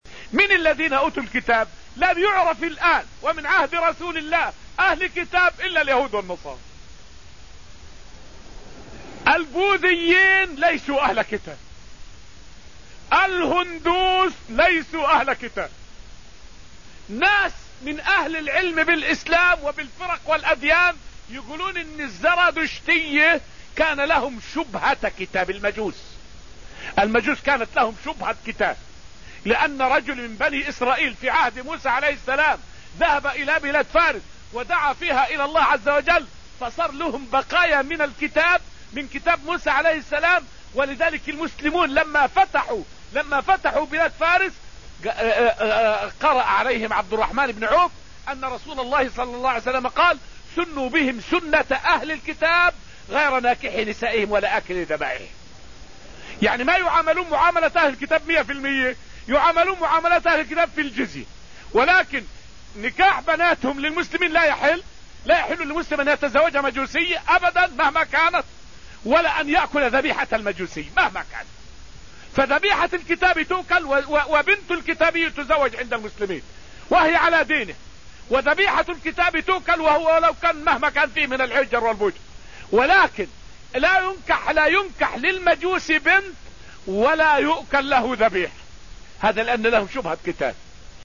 فائدة من الدرس التاسع عشر من دروس تفسير سورة الحديد والتي ألقيت في المسجد النبوي الشريف حول بعض الفروق بين أهل الكتاب وغيرهم.